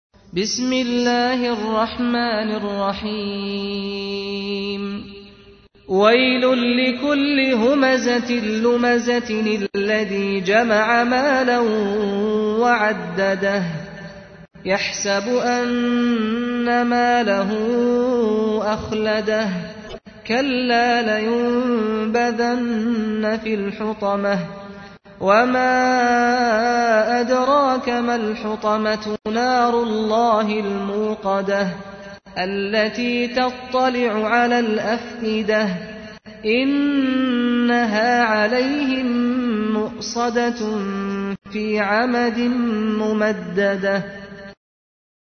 تحميل : 104. سورة الهمزة / القارئ سعد الغامدي / القرآن الكريم / موقع يا حسين